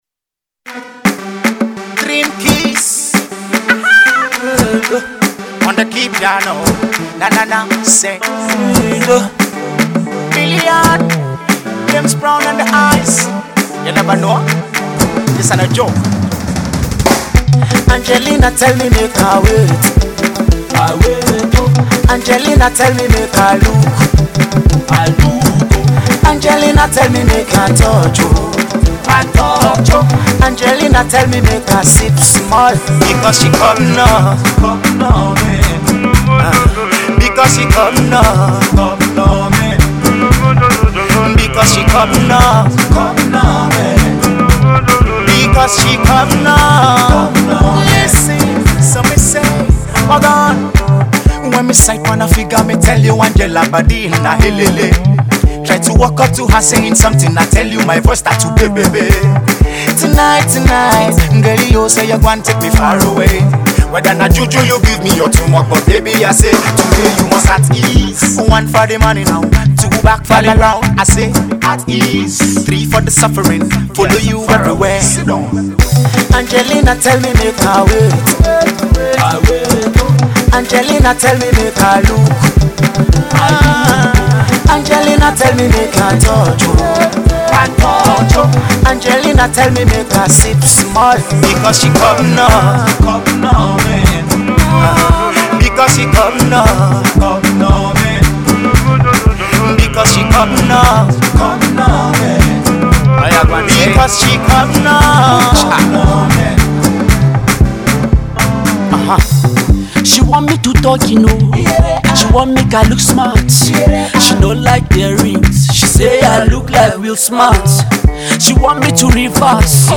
Decent pop song